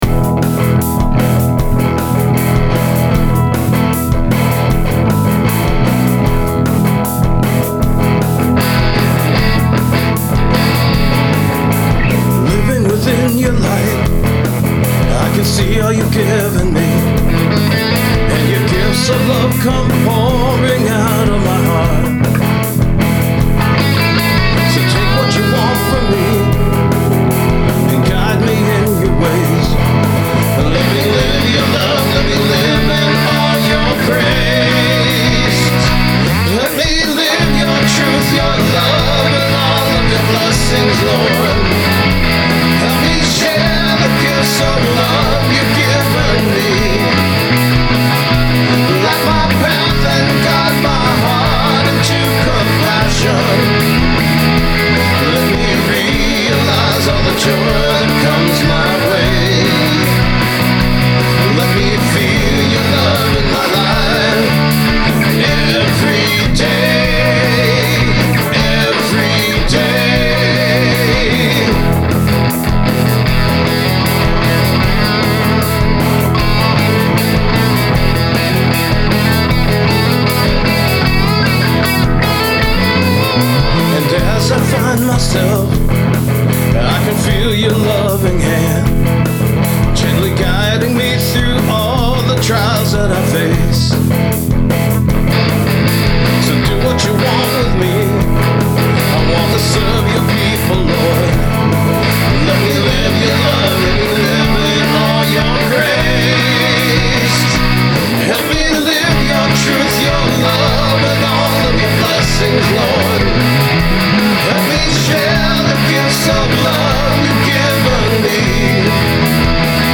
As far as instrumentation goes, both guitar tracks feature my Gibson ’58 Historic Les Paul running through my Aracom PLX18-BB Trem totally cranked up. The guitar parts were recorded at normal conversation levels, as I used my Aracon PRX150-Pro attenuator. I played my Ibanez bass for the bass part direct into my DAW, then used a Bassman model to get the sound I wanted.